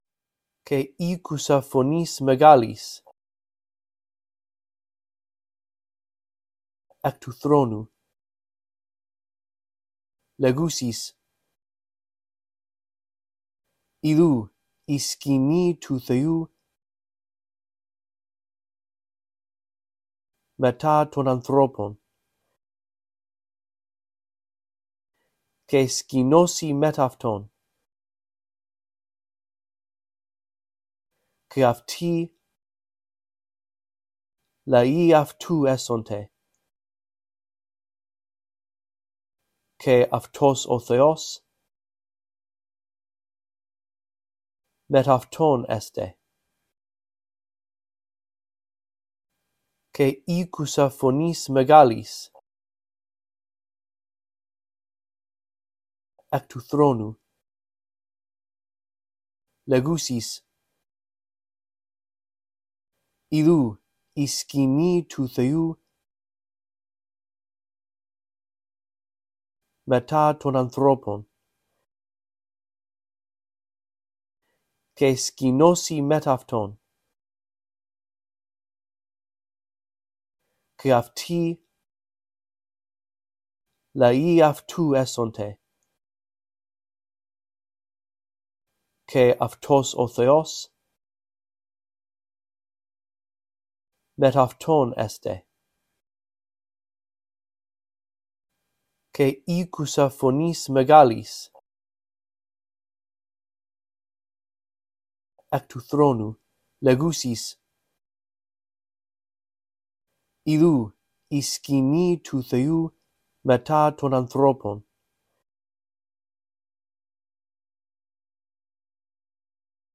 In this audio track, I read through verse 3 a phrase at a time, giving you time to repeat after me. After two run-throughs, the phrases that you are to repeat become longer.